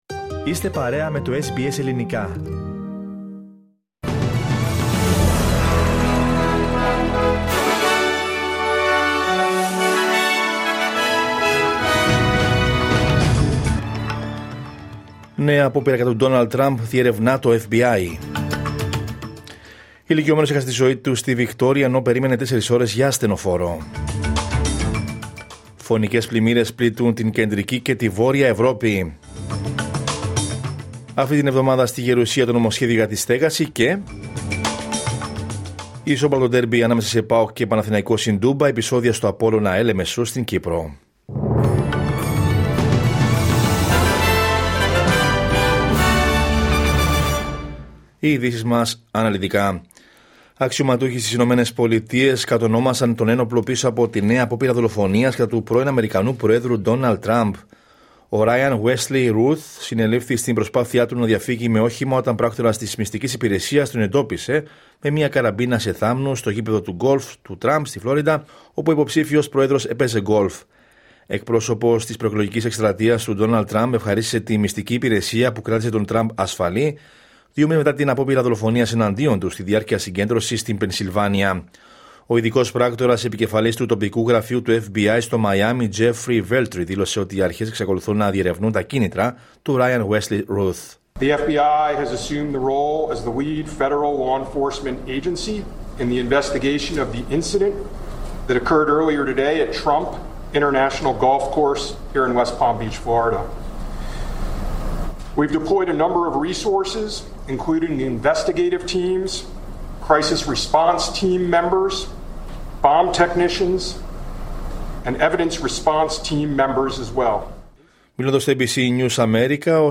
Δελτίο Ειδήσεων Δευτέρα 16 Σεπτεμβρίου 2024